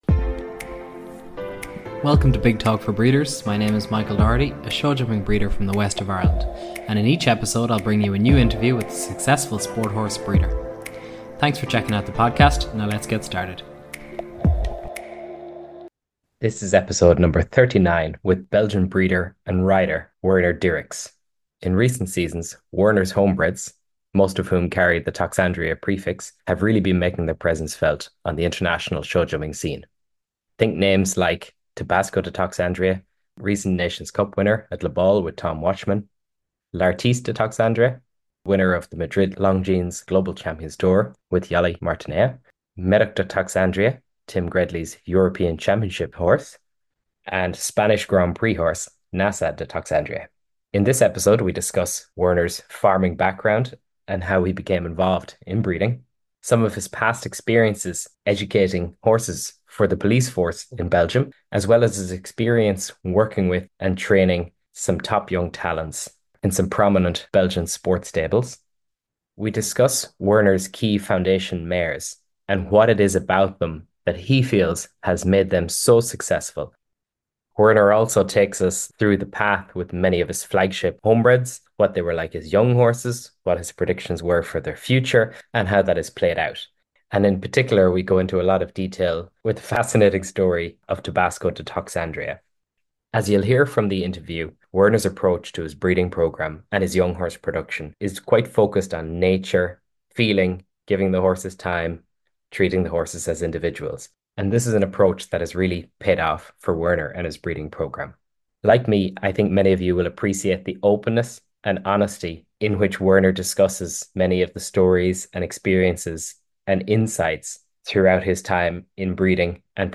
Play Rate Listened List Bookmark Get this podcast via API From The Podcast Big Talk For Breeders is a podcast series of interviews with internationally-successful sport horse breeders